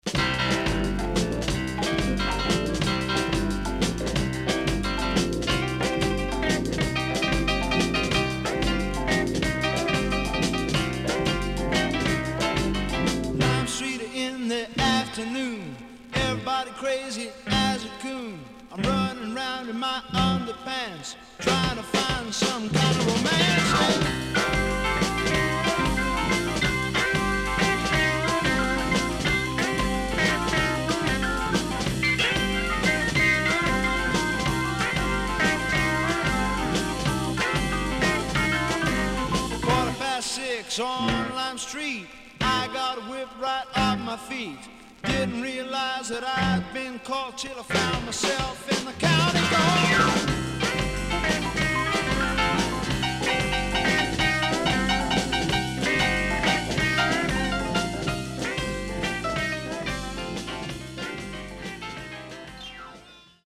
CONDITION SIDE A:VG+
SIDE A:少しチリノイズ入ります。